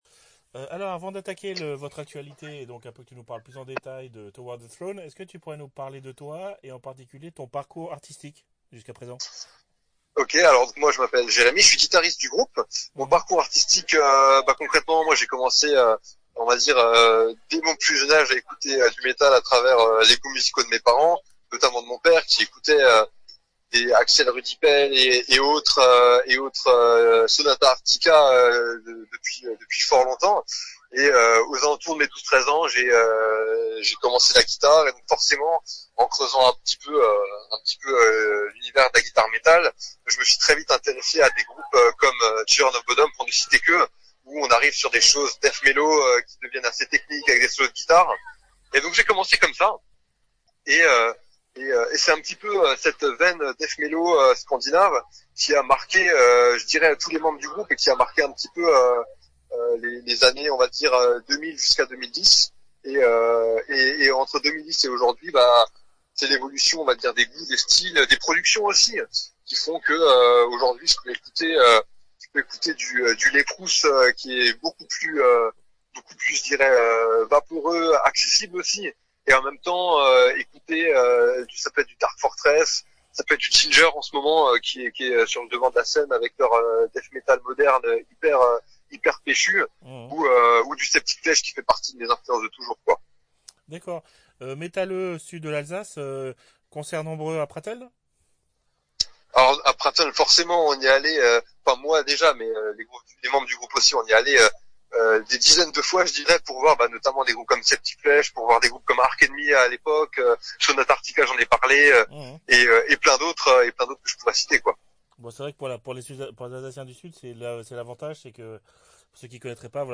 TOWARD THE THRONE (Interview